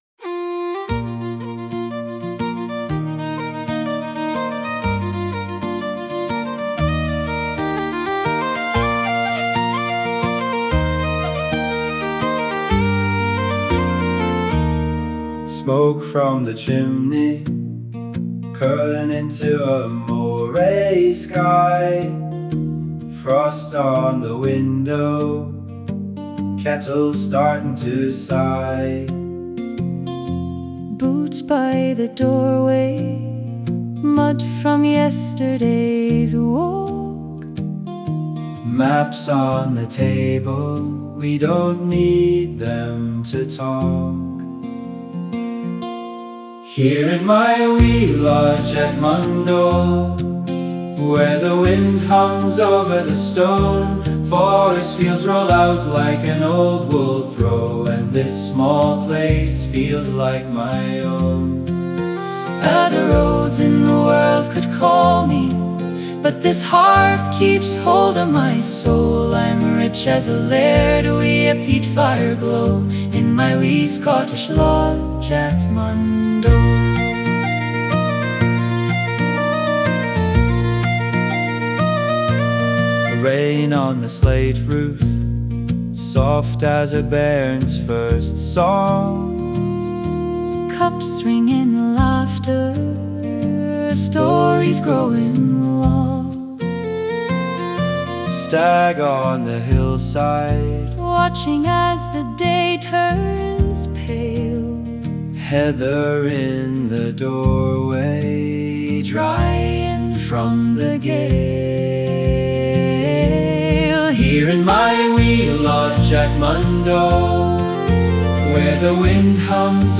Let's Start With a Wee Song: